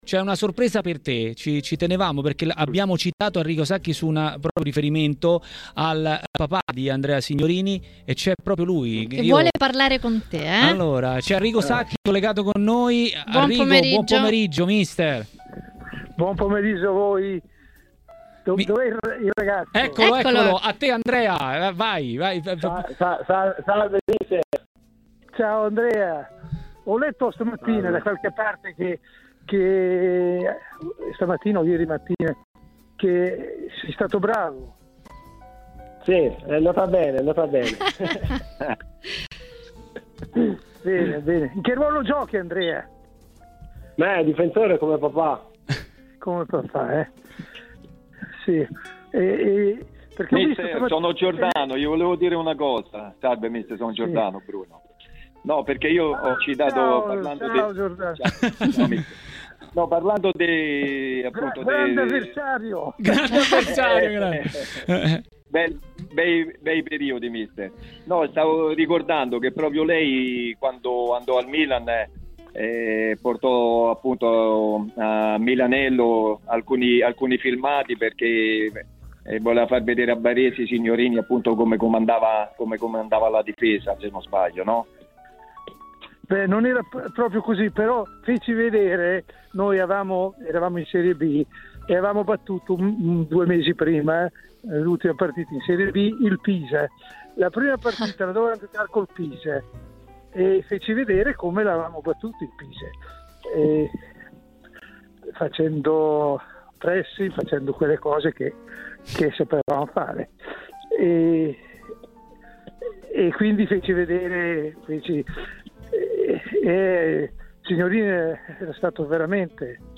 Un ospite a sorpresa a Maracanà, nel pomeriggio di TMW Radio.